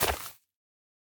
Minecraft Version Minecraft Version snapshot Latest Release | Latest Snapshot snapshot / assets / minecraft / sounds / block / nether_sprouts / break1.ogg Compare With Compare With Latest Release | Latest Snapshot